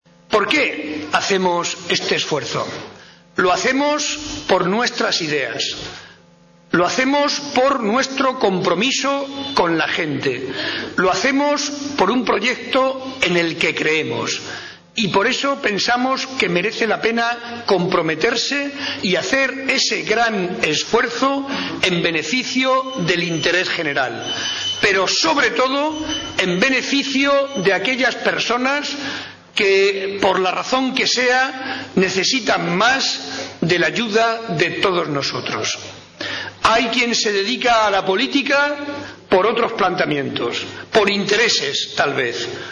El secretario regional del PSOE y presidente de Castilla-La Mancha, José María Barreda, se comprometió hoy desde Socuéllamos (Ciudad Real) a defender con firmeza y dedicación exclusiva los intereses de los castellano-manchegos frente a otros territorios.